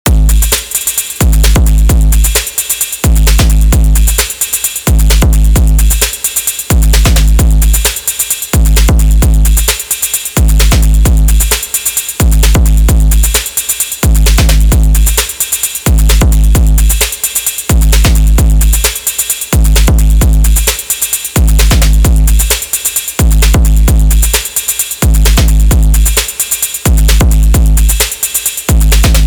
Find me another drum machine that can go from this
First one is kind of an overdriven 808 and second one yeah who knows but the distinct sounds it can make are not all that special.